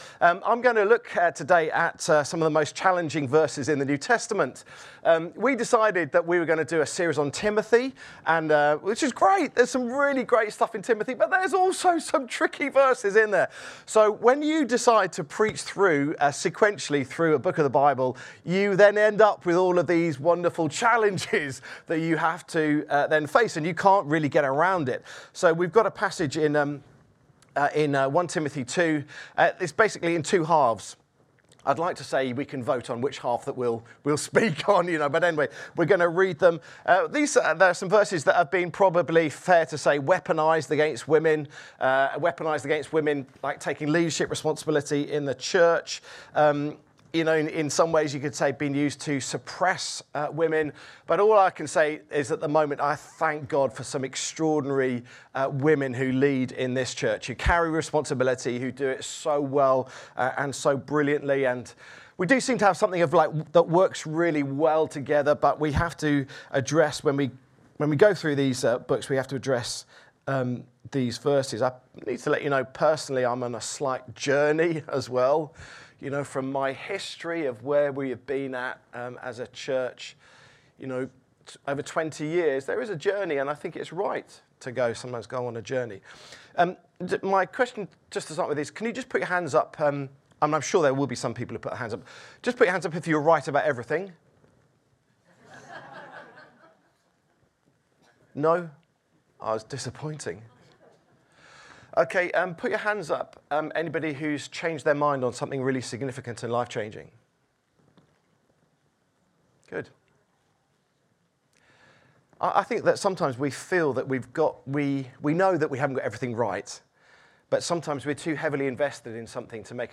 Download Women in worship | Sermons at Trinity Church